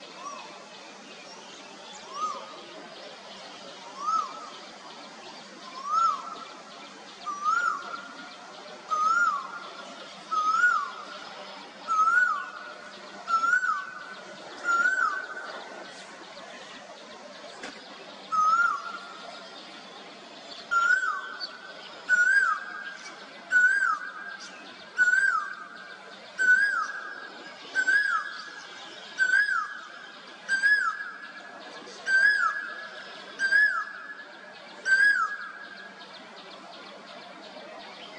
噪鹃鸟叫声